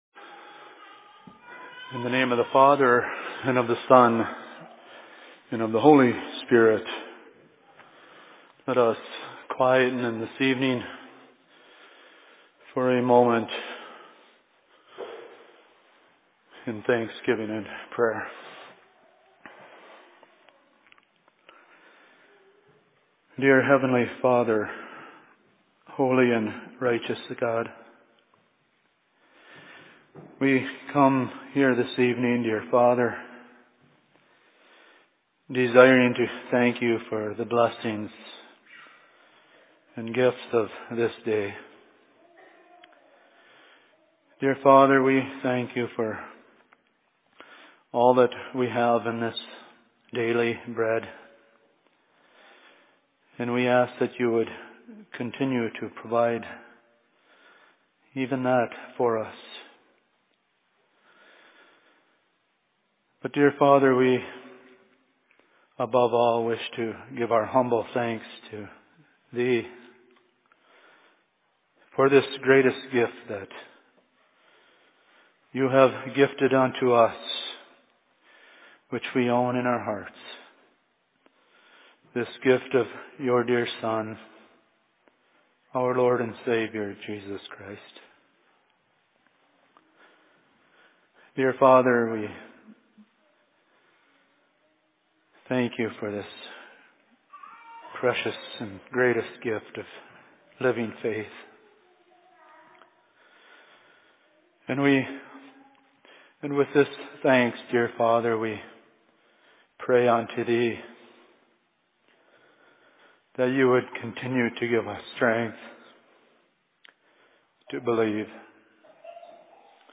Sermon in Cokato 11.12.2016
Location: LLC Cokato